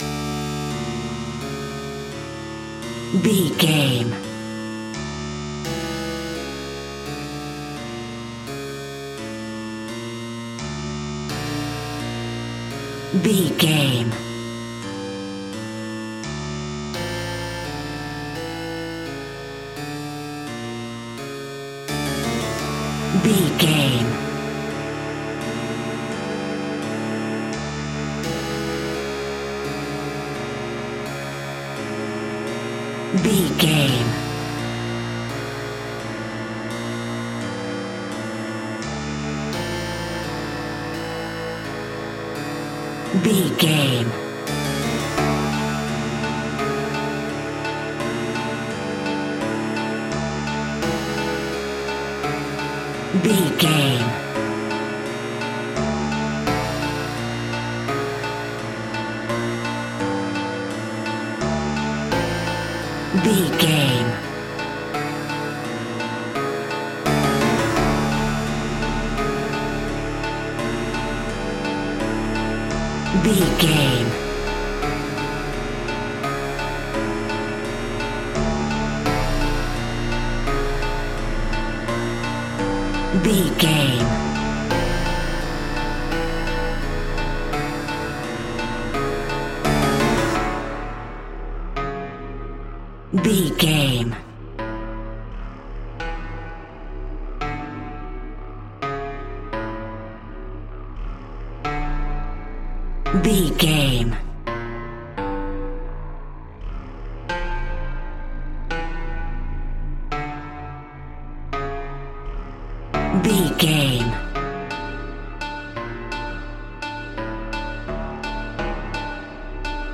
Horror Harpsichord.
In-crescendo
Aeolian/Minor
scary
ominous
dark
suspense
haunting
eerie
synthesizer
keyboards
ambience
pads